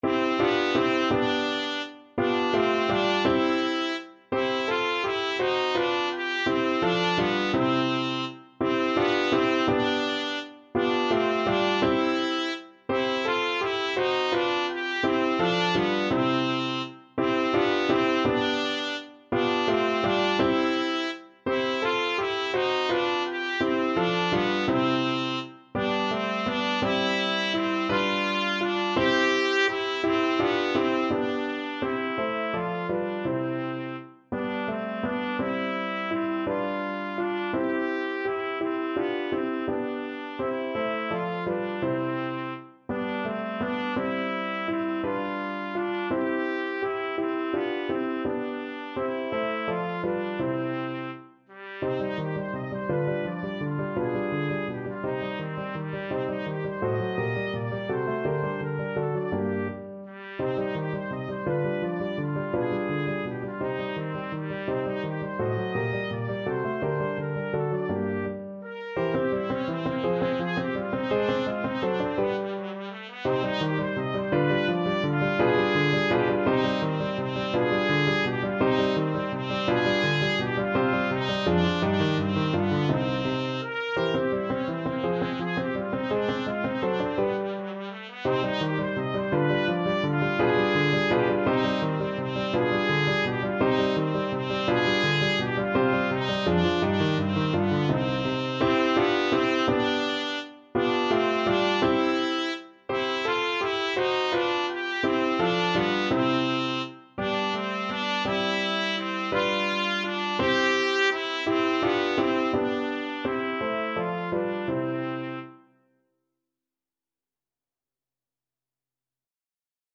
Trumpet
Lustig (Happy) .=56
3/8 (View more 3/8 Music)
Classical (View more Classical Trumpet Music)
bagatelle_cminor_TPT.mp3